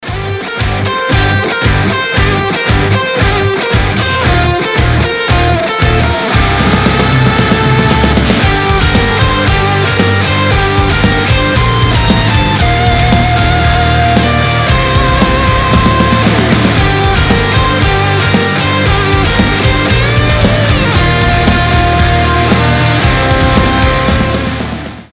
• Качество: 320, Stereo
ритмичные
атмосферные
без слов
инструментальные
электрогитара
энергичные
динамичные
бодрые
post-rock
инструментальный рок
пост-рок